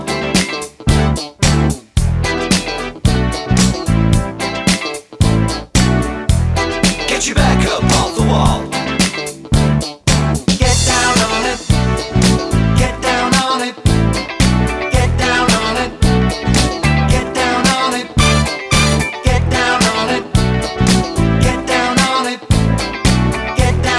no Backing Vocals Disco 4:48 Buy £1.50